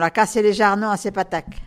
Localisation Sainte-Foy
Catégorie Locution